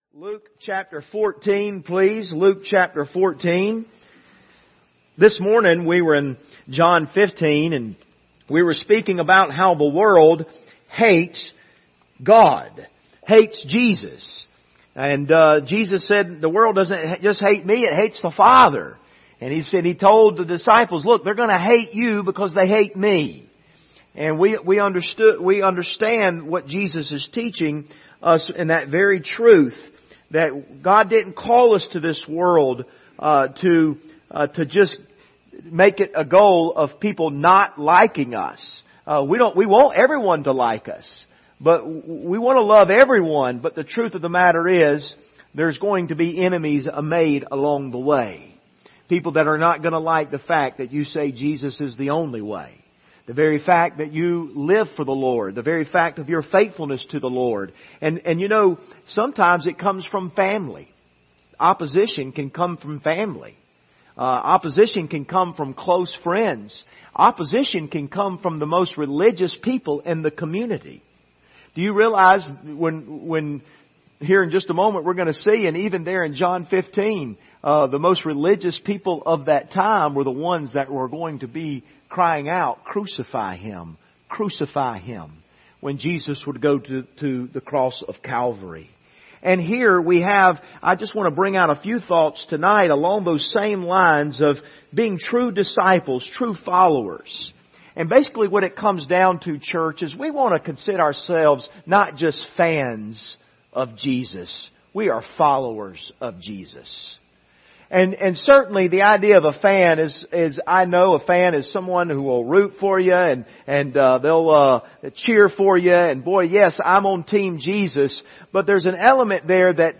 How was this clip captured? General Passage: Luke 14:25-27 Service Type: Sunday Evening Topics